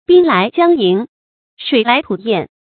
注音：ㄅㄧㄥ ㄌㄞˊ ㄐㄧㄤˋ ㄧㄥˊ ，ㄕㄨㄟˇ ㄌㄞˊ ㄊㄨˇ ㄧㄢˋ